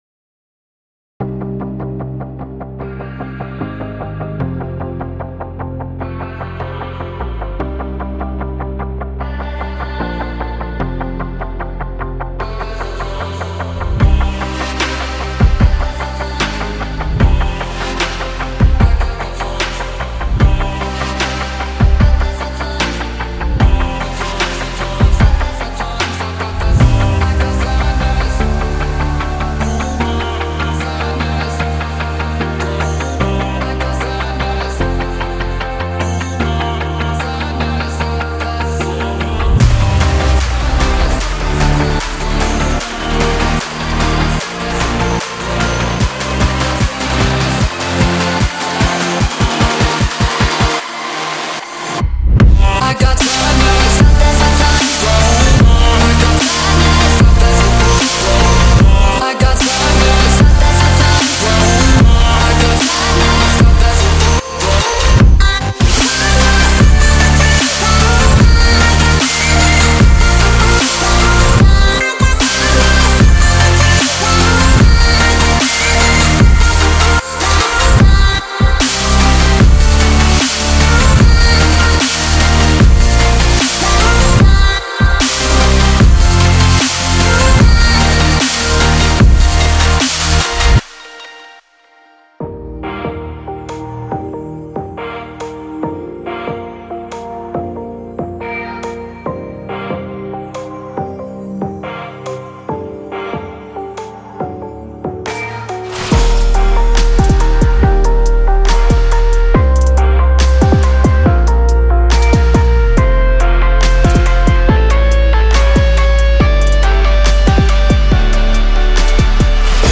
这是一个充满创造力的编辑，充满了未来低音的音色，可以添加到您的收藏中。
您会发现50个干声带和50个湿声带回声循环完成，所有制作都准备就绪，可让您深入音乐领域并带给您优势。